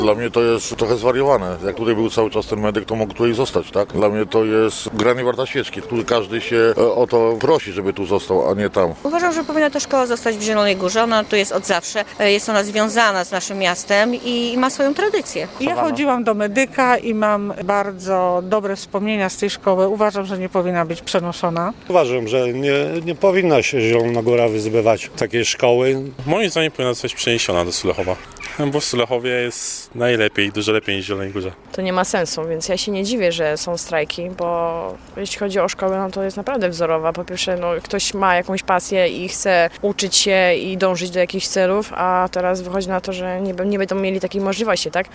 Czy „Medyk” powinien zostać przeniesiony do Sulechowa? [SONDA]
Zapytaliśmy mieszkańców, co sądzą o rezultacie wczorajszego głosowania: